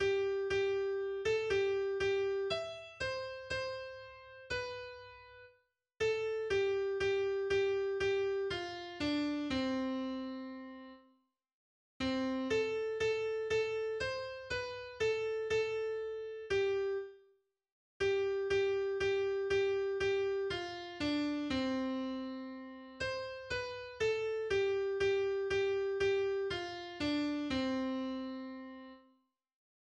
das nach einer Volksweise gesungen wird.